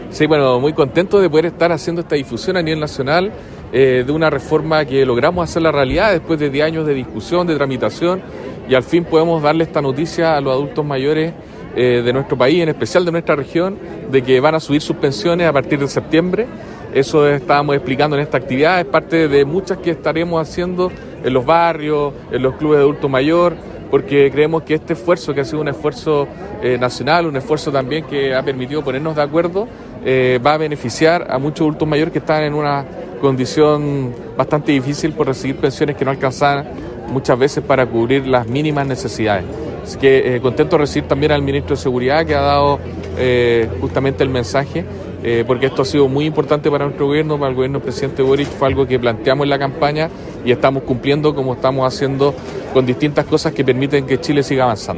En un encuentro ciudadano con vecinos y vecinas de Coquimbo, se dieron a conocer los beneficios e hitos de la implementación de la nueva ley previsional